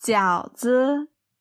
Jiǎo zi
ジャオ ズ